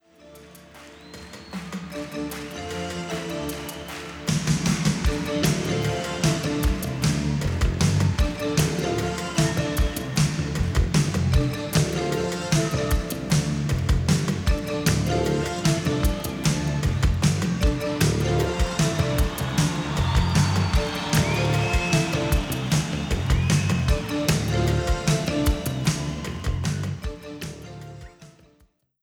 Compare Sample from original CD to newly Digital Refresh.